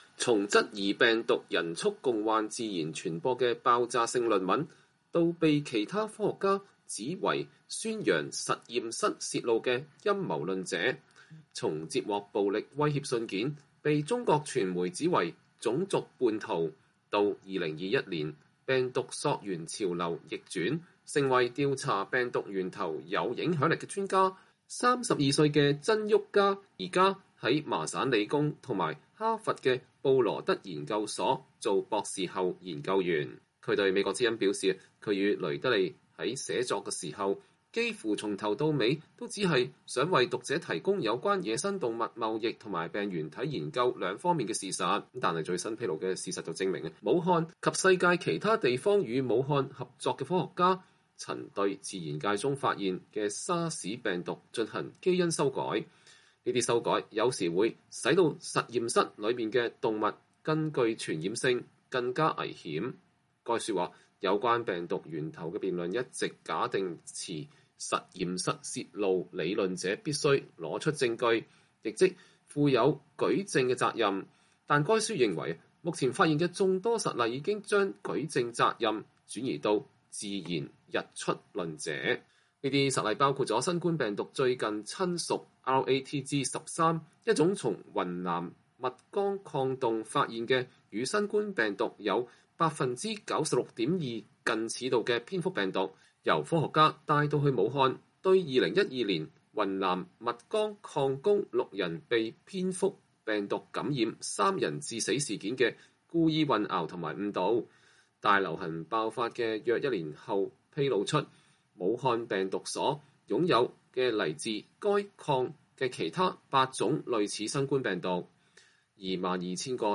以下是美國之音對亞裔科學家曾昱嘉的專訪實錄：